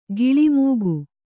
♪ giḷi mūgu